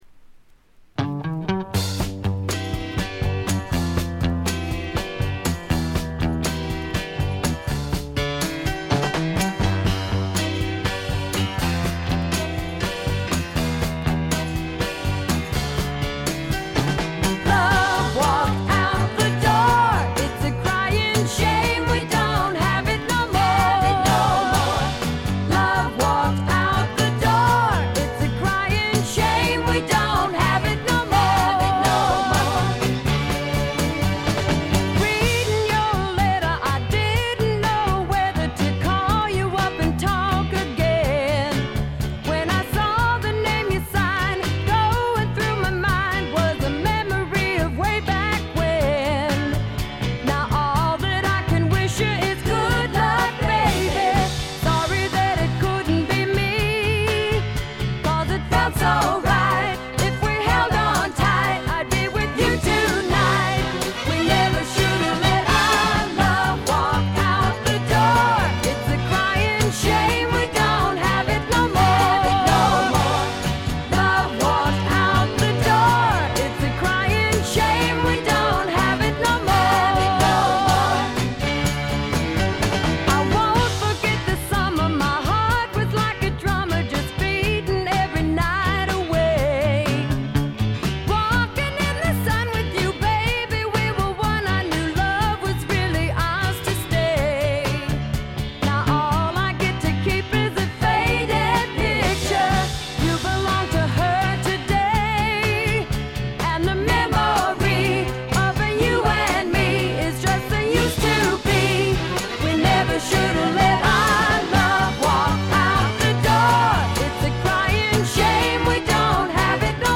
軽微なチリプチ少々。
ソウル系のカヴァーでは力強いシャウトで迫力のある歌声を聴かせてくれます。
スワンプ系女性ヴォーカル・アルバムの快作です。
試聴曲は現品からの取り込み音源です。